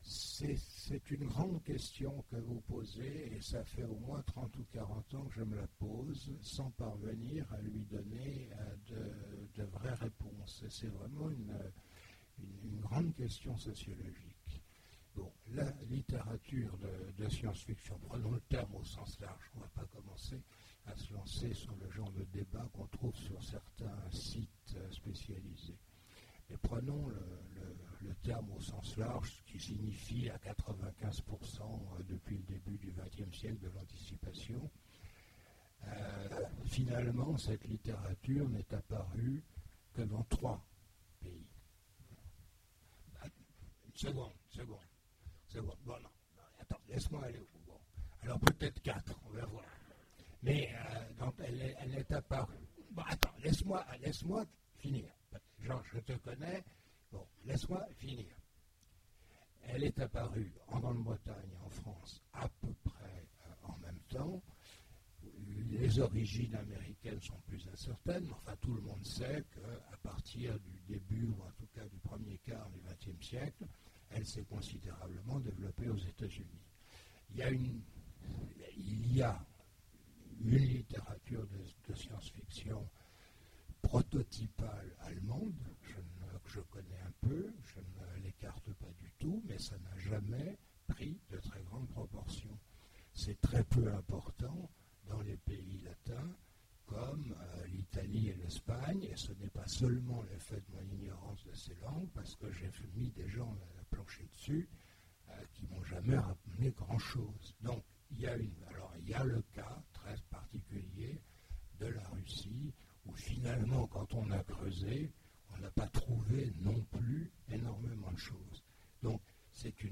Voici l'enregistrement de la conférence avec Gérard Klein aux rencontres de l'Imaginaire de Sèvres du 12 décembre 2009 à l'occasion des 40 ans d'Ailleurs et demain.
Les questions du public: